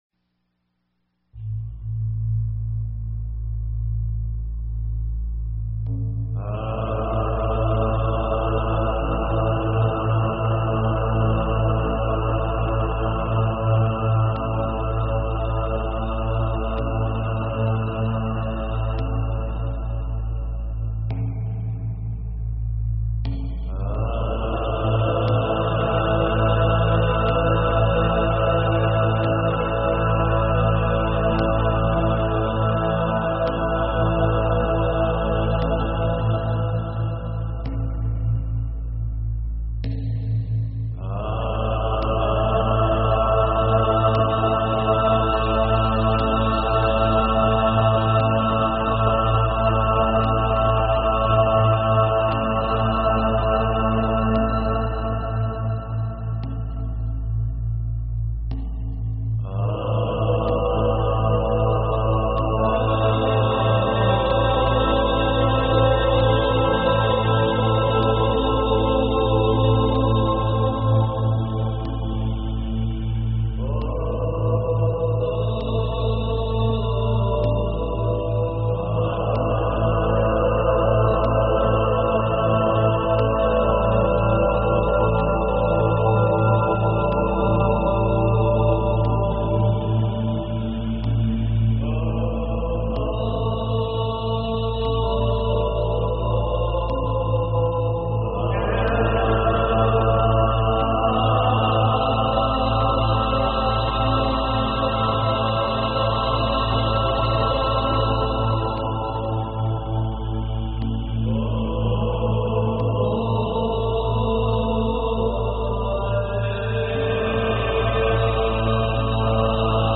Talk Show Episode, Audio Podcast, DreamPath and Courtesy of BBS Radio on , show guests , about , categorized as
Comedy, music, and a continuous weaving of interviews and story telling (DreamPath style) covering numerous alternative thought topics.